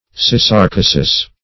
Search Result for " syssarcosis" : The Collaborative International Dictionary of English v.0.48: Syssarcosis \Sys`sar*co"sis\, n. [NL., fr. Gr.